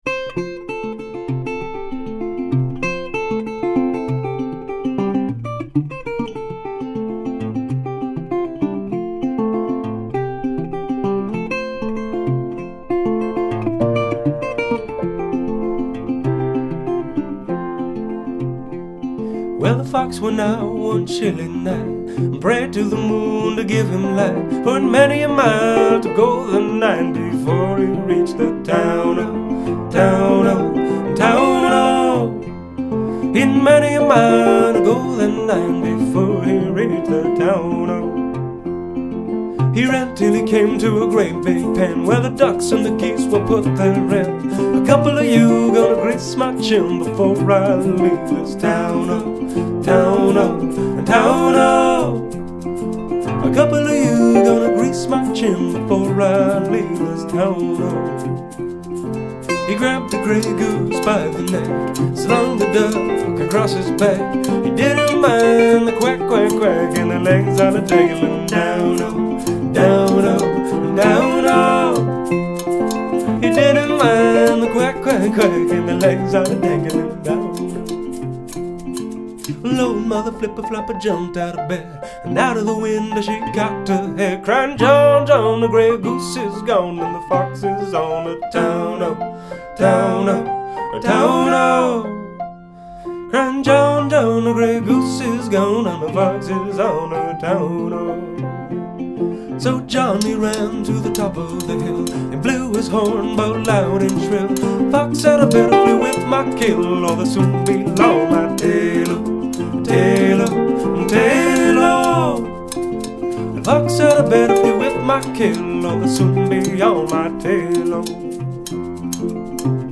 piano
percussion
here’s a Burl Ives children’s tune we recorded in a Congolese rumba style
vocals/guitar